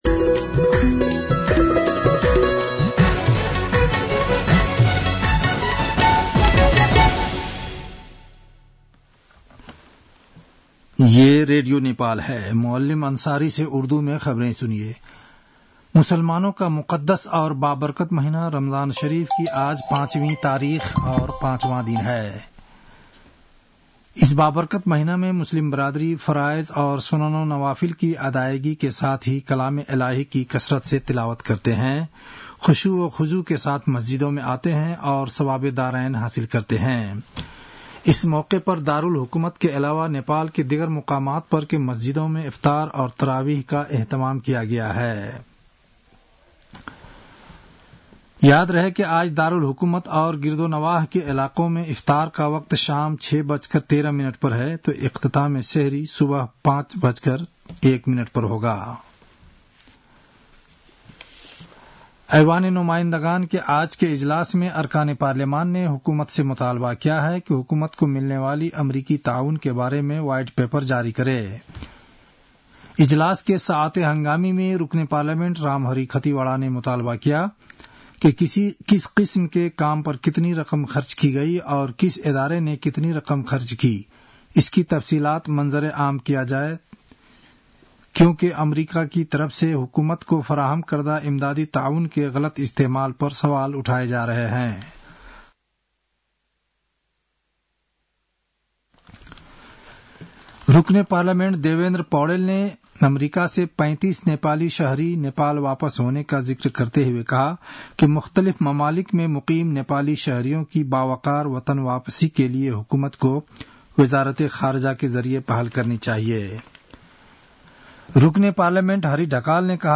उर्दु भाषामा समाचार : २३ फागुन , २०८१